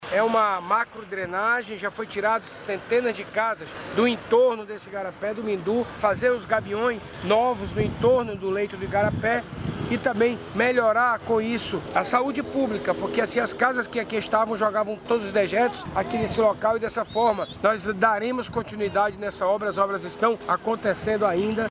Nesta nova fase, a Seminf trabalha na contenção das margens, nos trechos do Parque Linear 1 e da Galeria que fica sob a avenida Autaz Mirim, conforme destaca Renato Júnior.